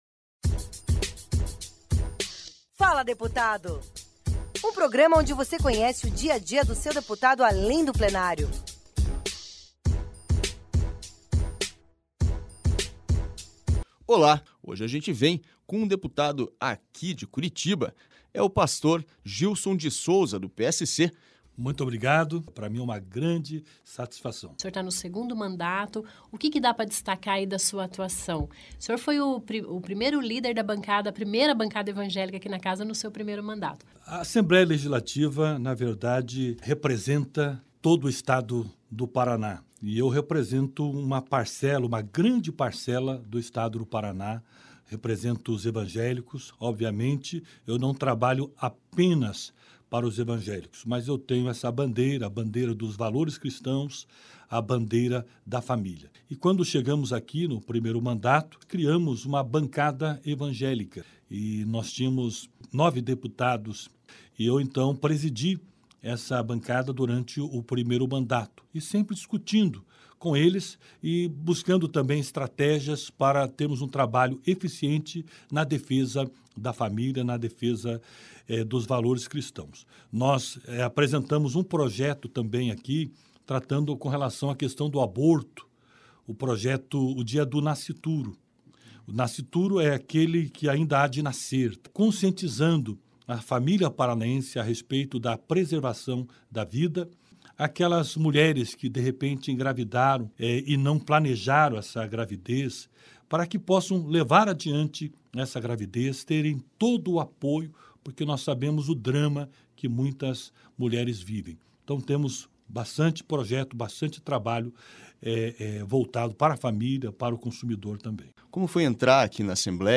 O programa “Fala Deputado” desta semana leva ao ar a entrevista com Gilson de Souza, do PSC, curitibano da Água Verde que foi líder da primeira bancada evangélica na Assembleia Legislativa.